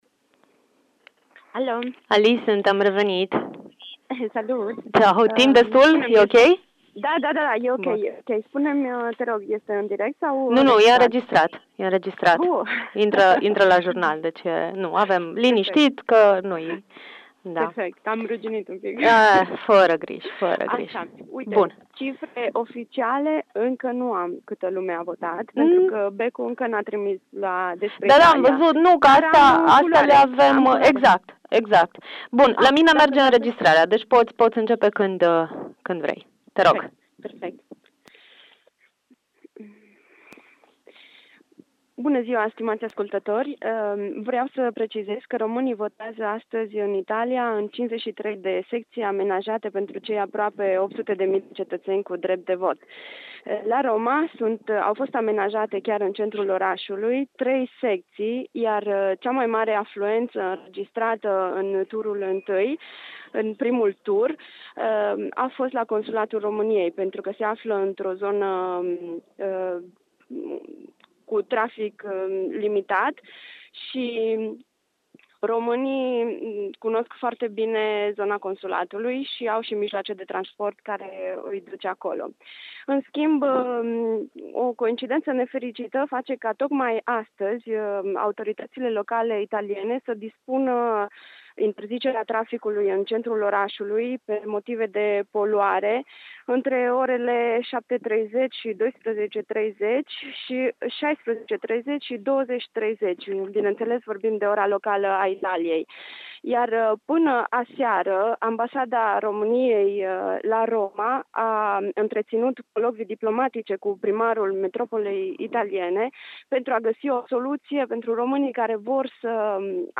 Transmite din capitala Italiei în exclusivitate pentru Radio Timișoara